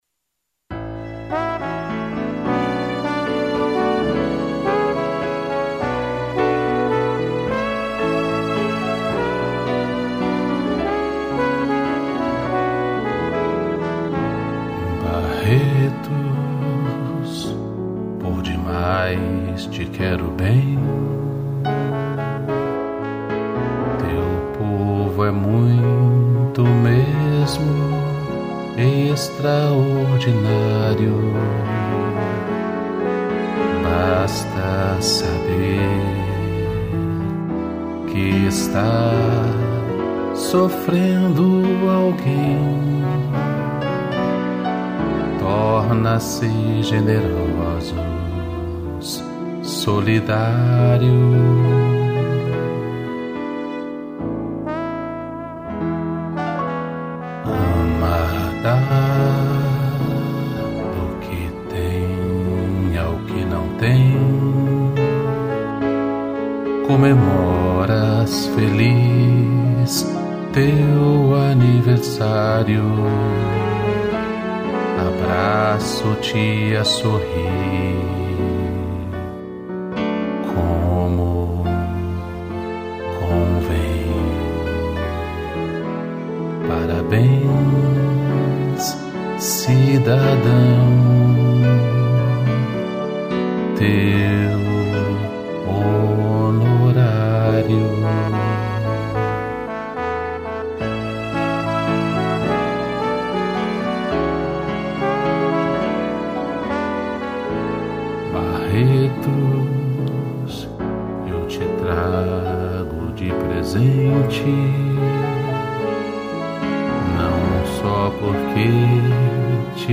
2 pianos, trombone e violino